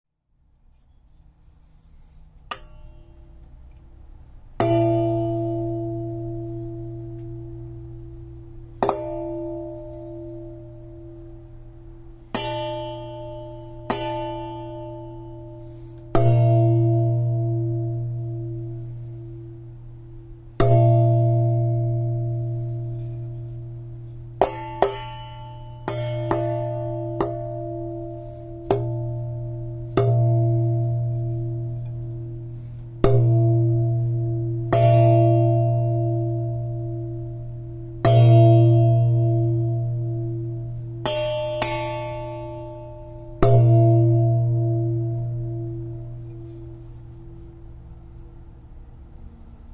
Lovely rich, deep bell sounds from hitting a mixing bowl with a hammer.